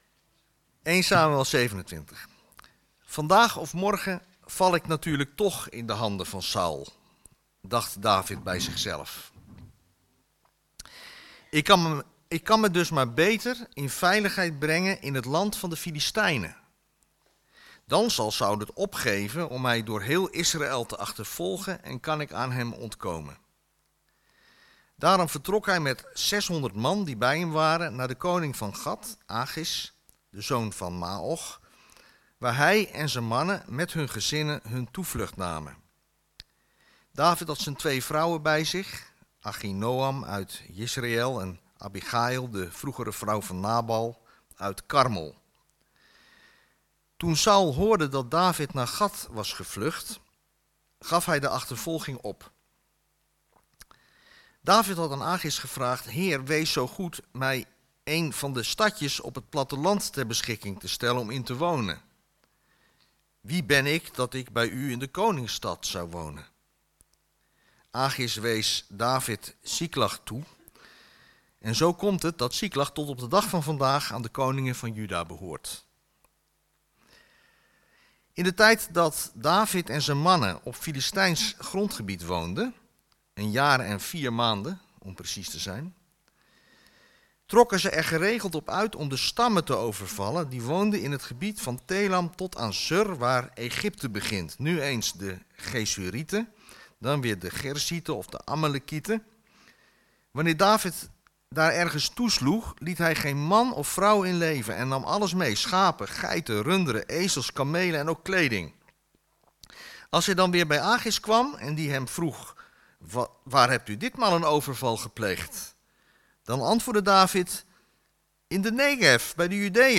Preken
preek-2-november-2025.mp3